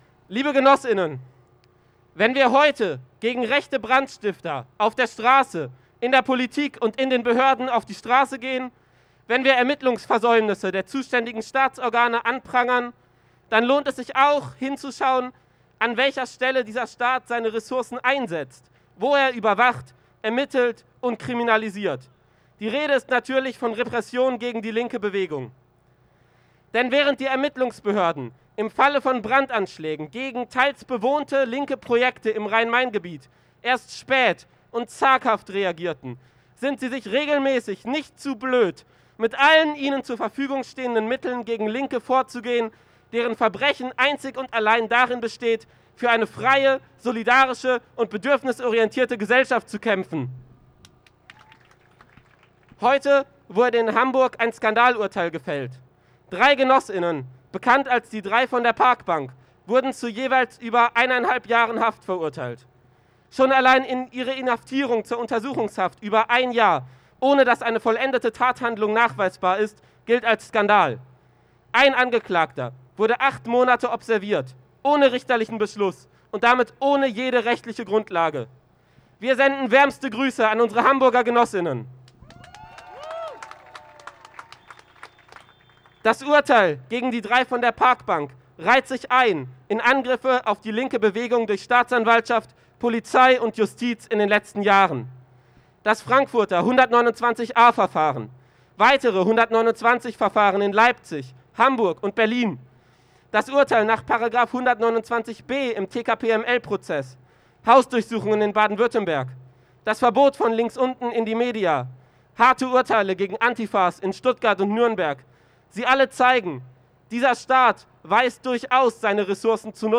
Redebeiträge der Demonstration